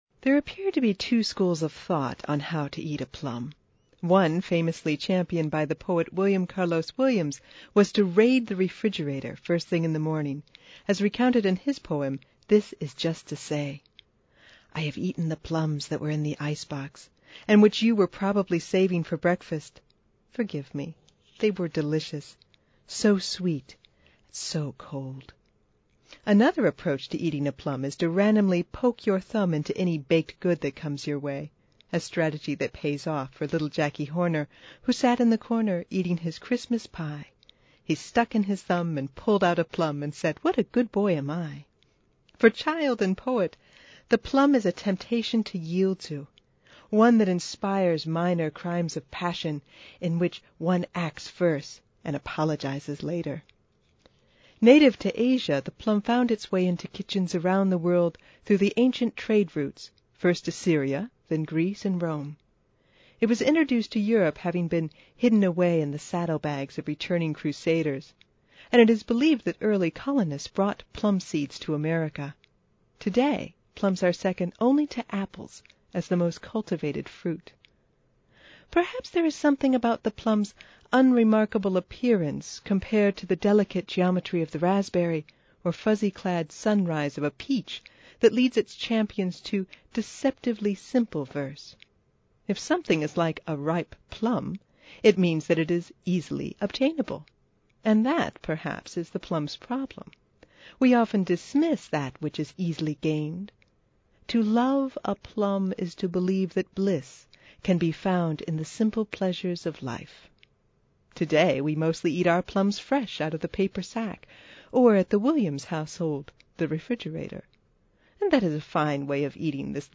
Originally aired on WMUB-FM, September 2008, “Purple Haze” was the winner a 2009 Public Radio News Directors, Inc. (PRINDI) award and Ohio Associated Press Award for best broadcast writing.